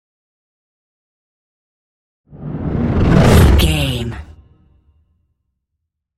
Whoosh deep
Sound Effects
dark
futuristic
intense